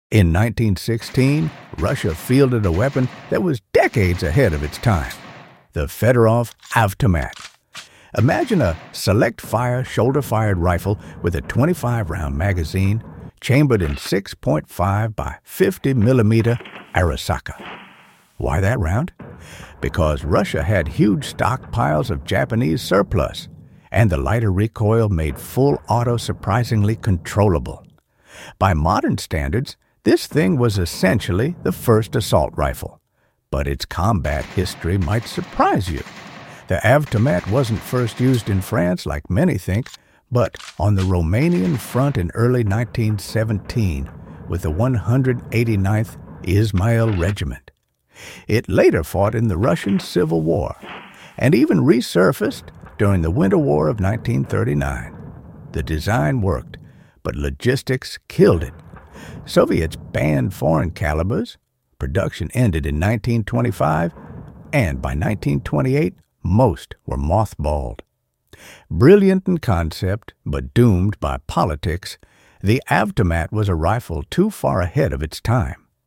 Fedorov Avtomat Sound Effects Free Download